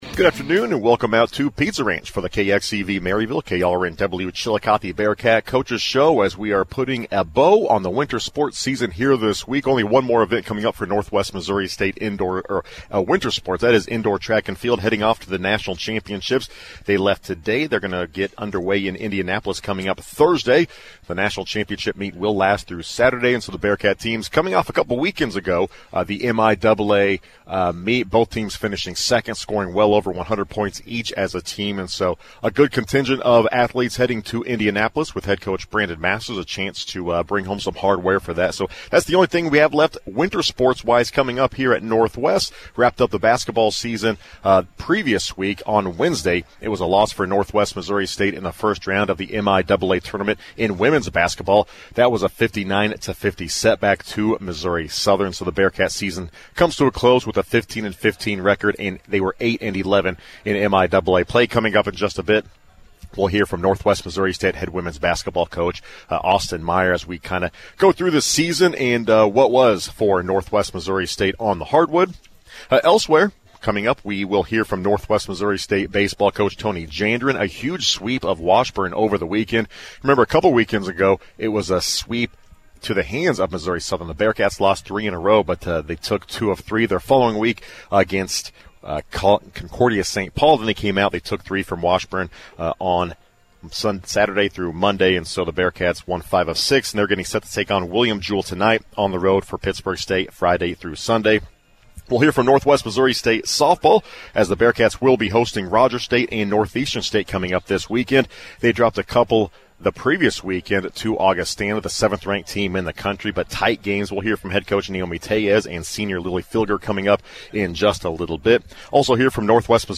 KXCV-KRNW is the public radio service of Northwest Missouri State University, Maryville.
Local Sports